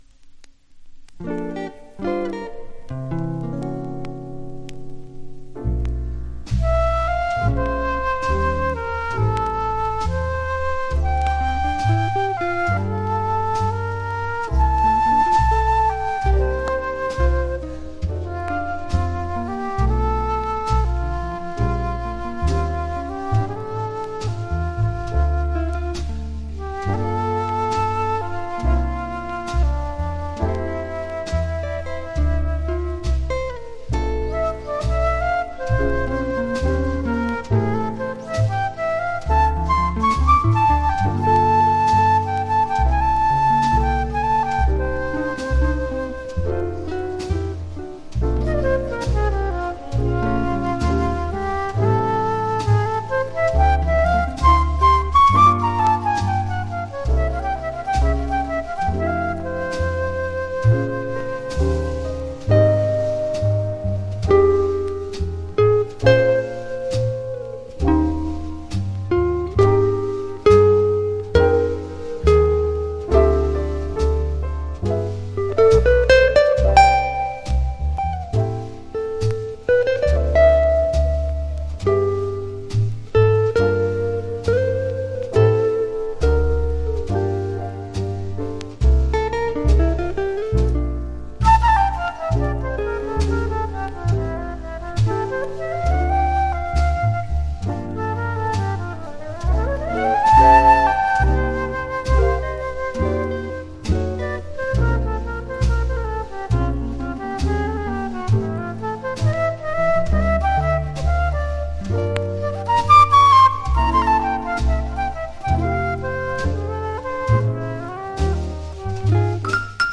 （プレス・小傷によりチリ、プチ音ある曲あり）
Genre US JAZZ